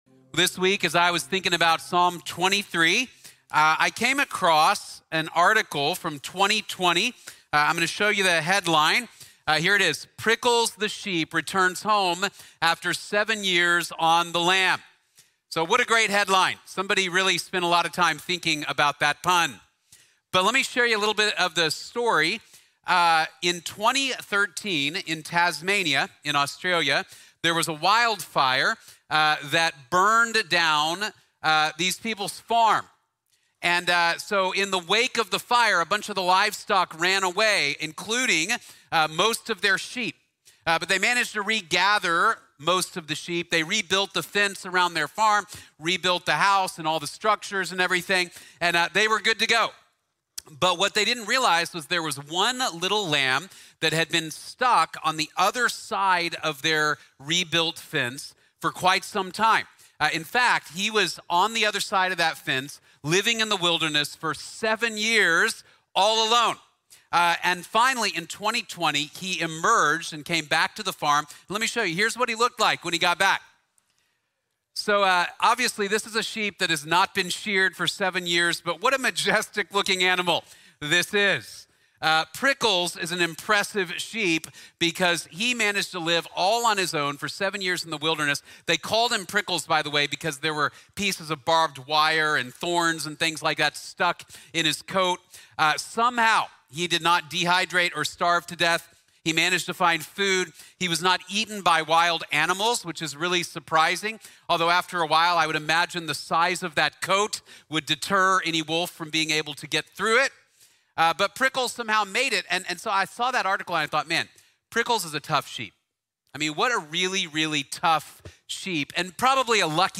The Lord is My Shepherd | Sermon | Grace Bible Church